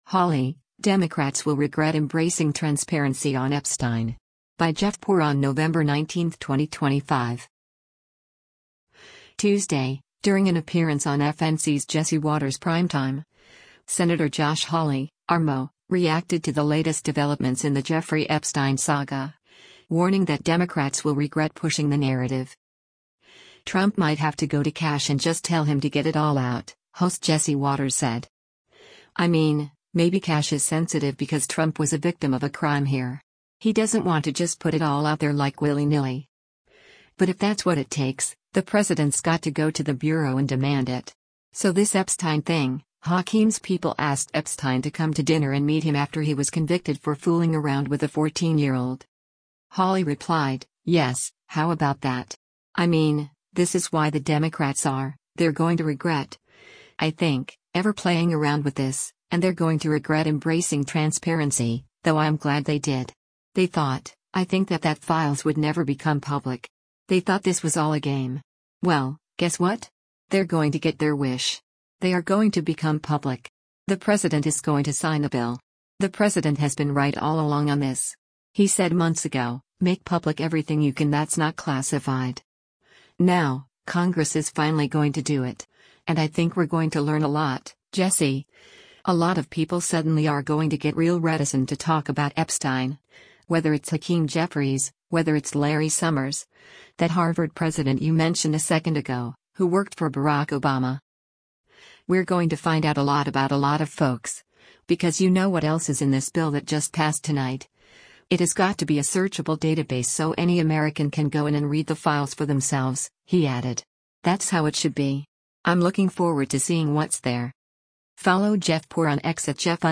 Tuesday, during an appearance on FNC’s “Jesse Watters Primetime,” Sen. Josh Hawley (R-MO) reacted to the latest developments in the Jeffrey Epstein saga, warning that Democrats will regret pushing the narrative.